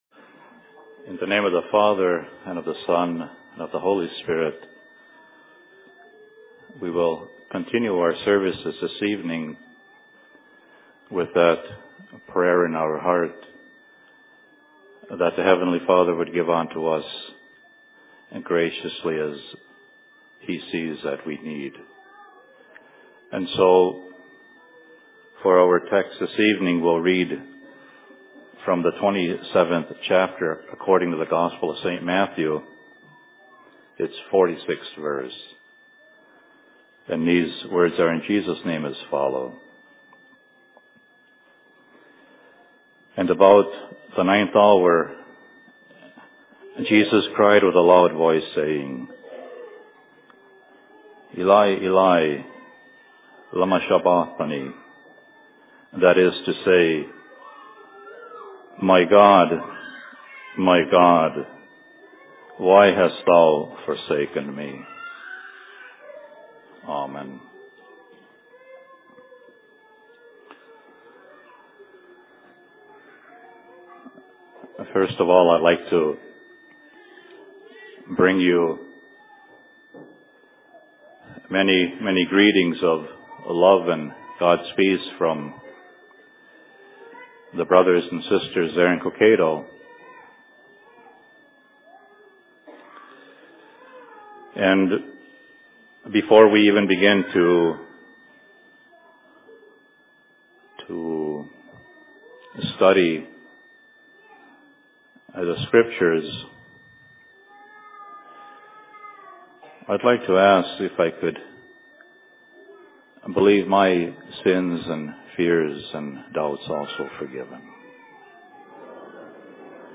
Sermon in Outlook 21.03.2008
Location: LLC Outlook